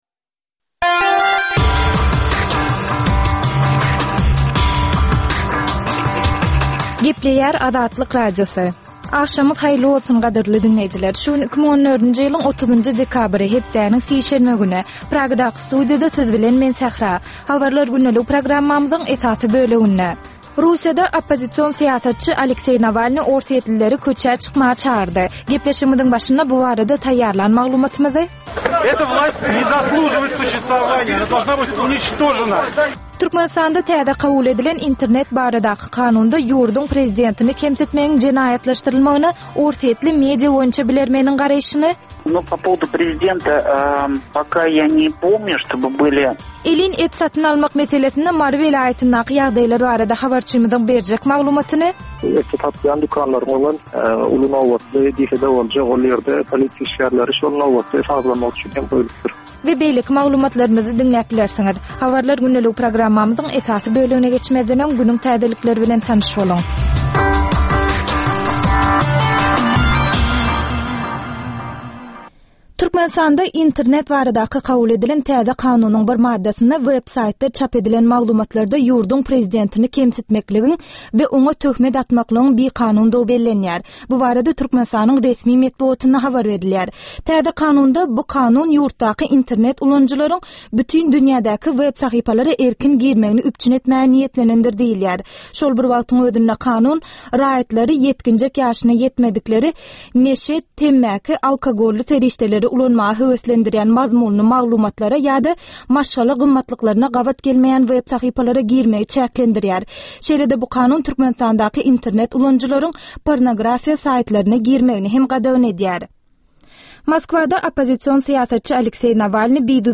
Dünýäniň dürli regionlarynda we Türkmenistanda şu günki bolan we bolup duran soňky wakalar barada gysgaça habarlar.